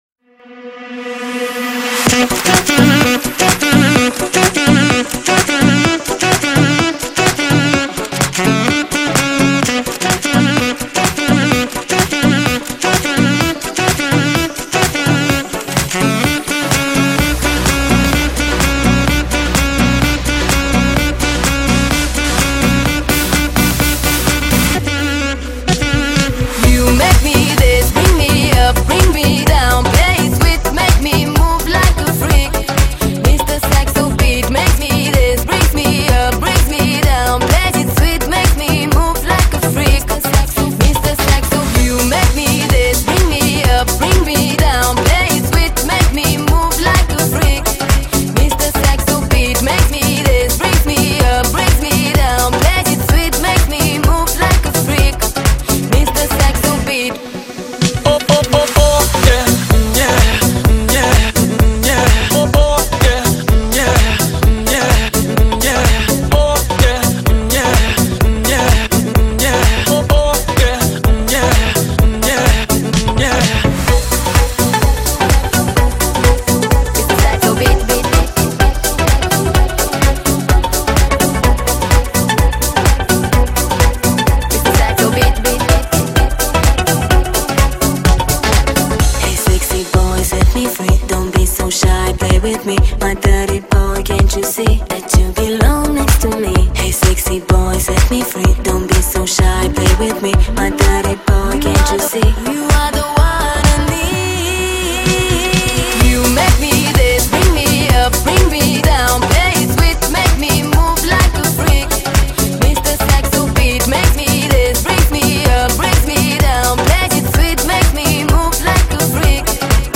ჟანრი: Pop / Electro